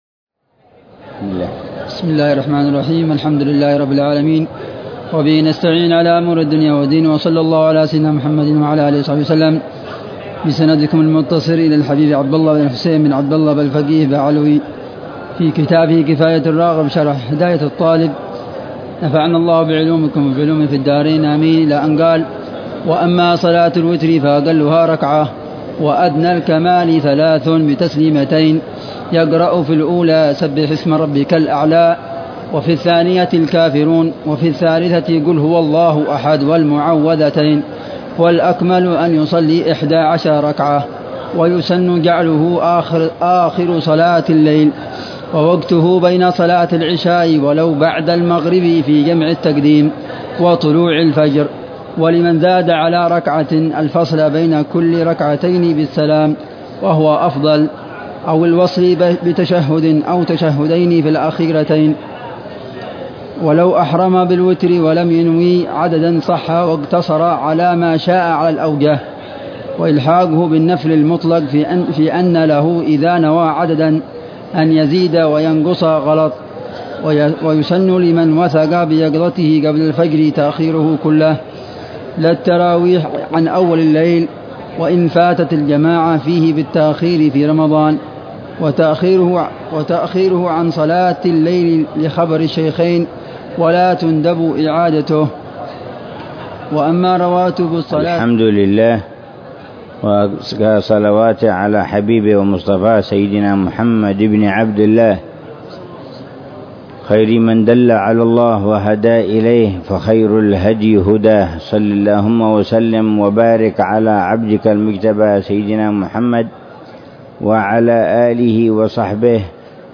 شرح الحبيب عمر بن حفيظ على كتاب كفاية الراغب شرح هداية الطالب إلى معرفة الواجب للإمام العلامة عبد الله بن الحسين بن عبد الله بلفقيه.